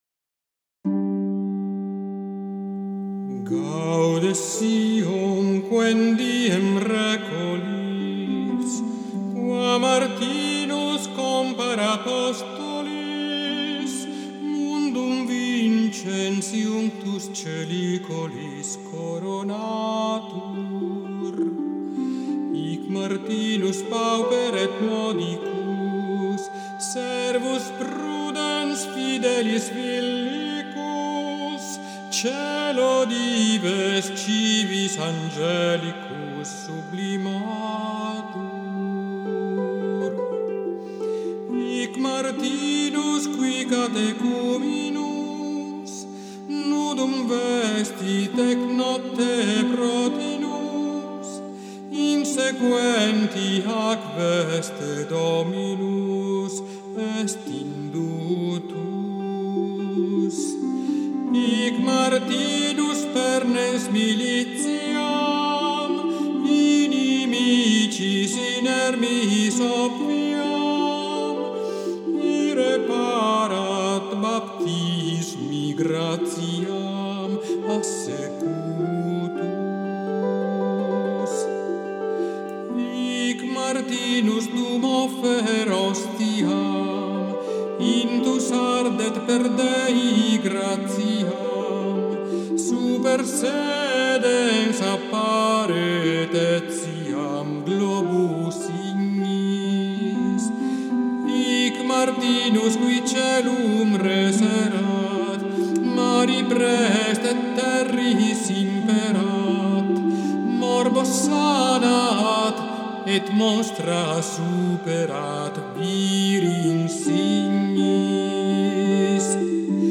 morto artigianalmente ed elettronicamente, una sequenza di Adamo di San Vittore, Gaude Sion, che racconta alcuni episodi della vita del nostro.